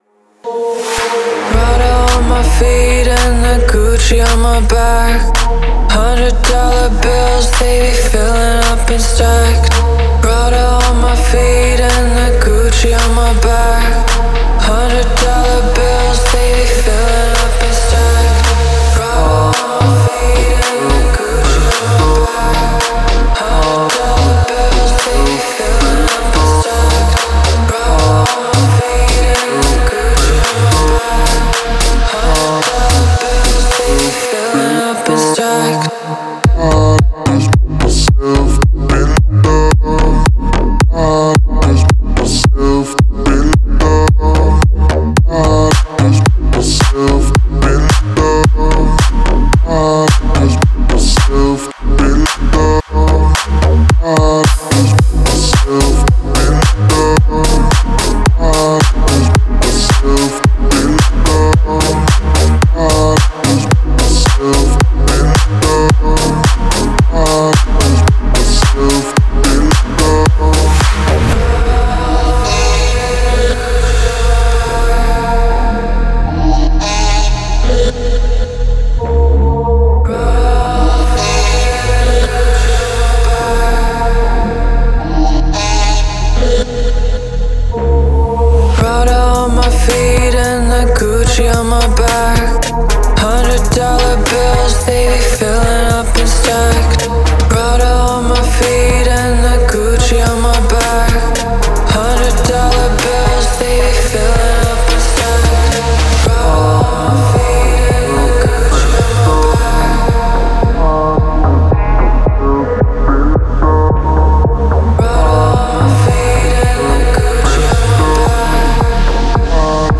это трек в жанре поп с элементами электронной музыки